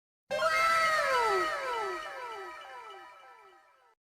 woooow.wav